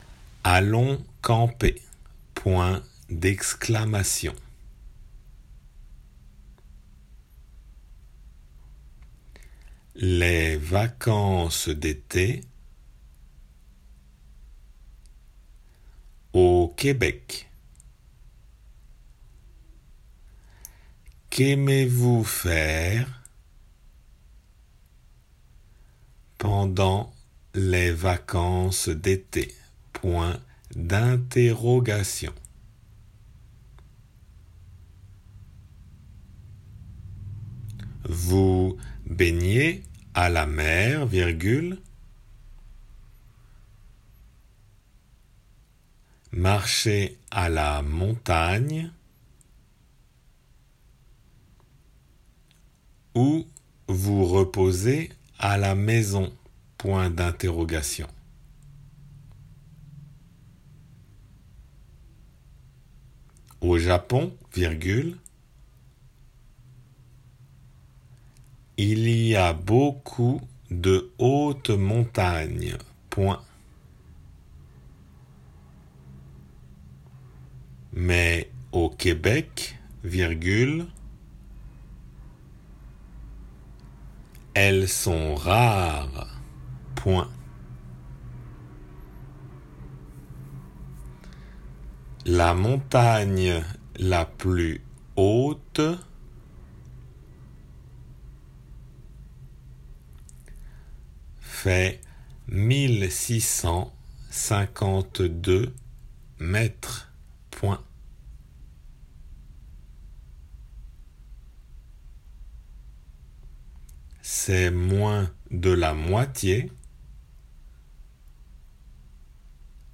デイクテの練習
デイクテの速さで